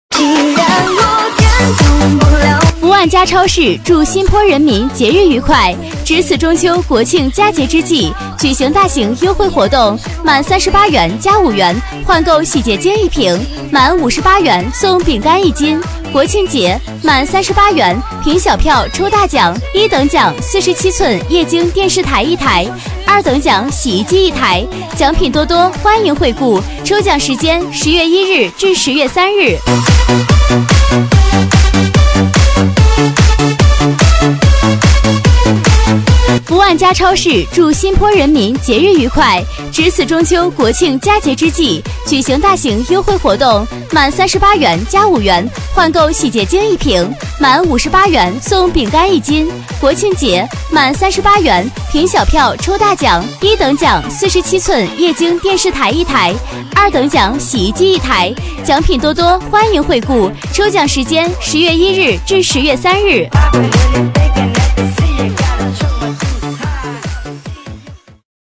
B类女13
【女13号促销】福万佳超市